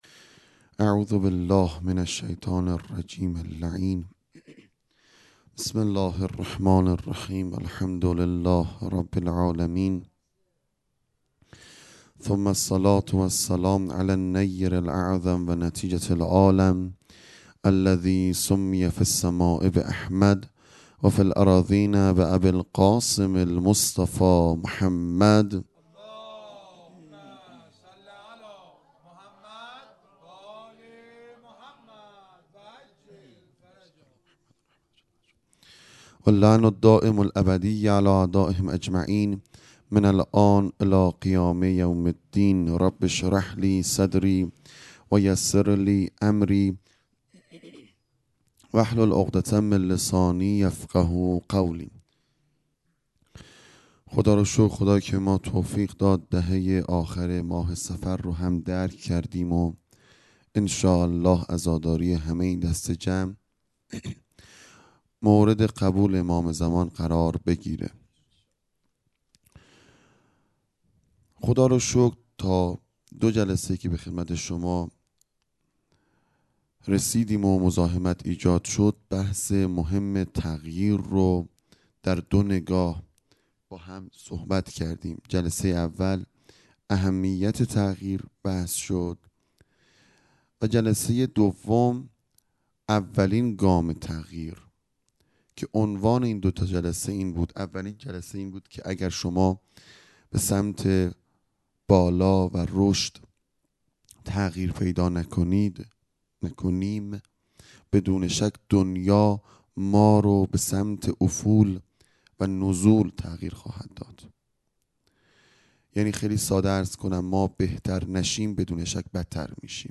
0 0 سخنرانی